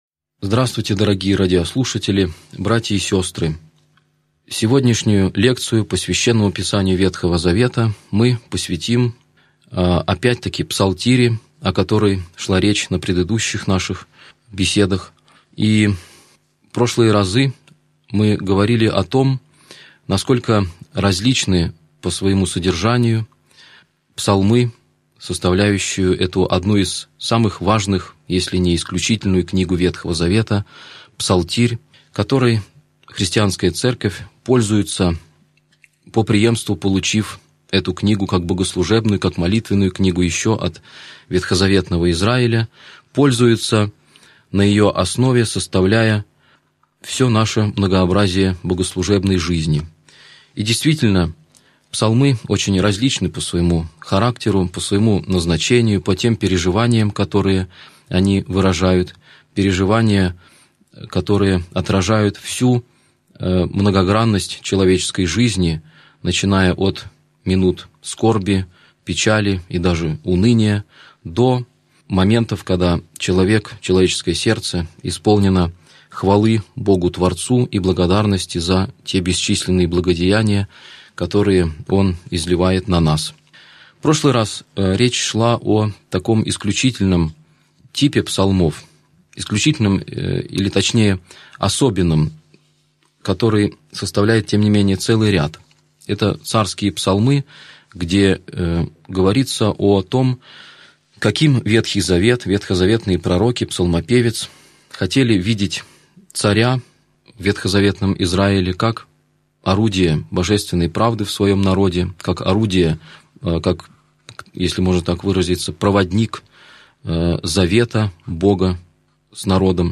Аудиокнига Лекция 33. Псалмы мессианские | Библиотека аудиокниг